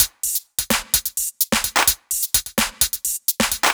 Index of /musicradar/french-house-chillout-samples/128bpm/Beats
FHC_BeatA_128-01_HatClap.wav